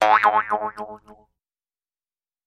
Такое звучание у кубыза: татарский и башкирский музыкальный инструмент